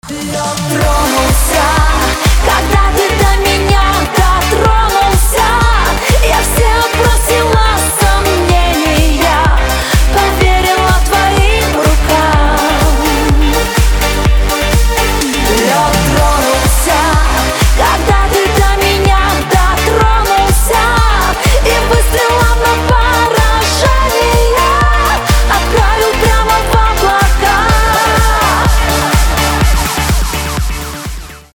• Качество: 320, Stereo
поп
громкие